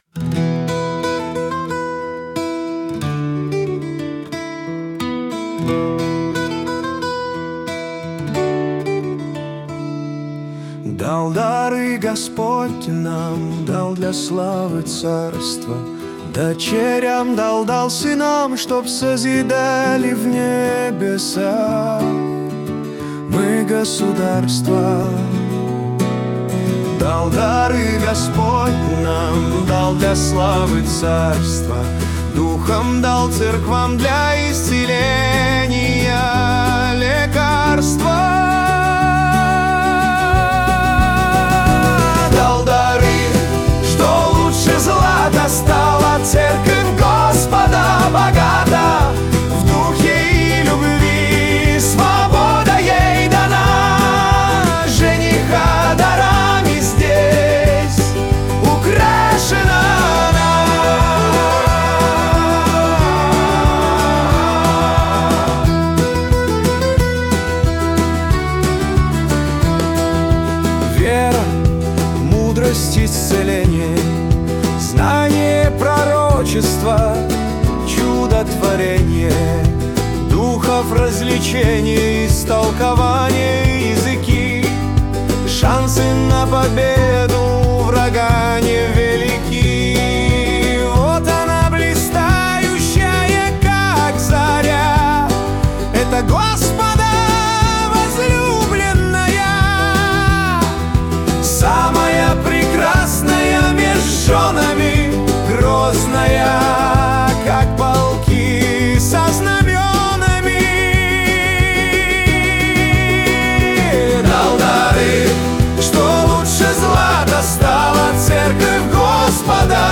песня ai
249 просмотров 830 прослушиваний 97 скачиваний BPM: 181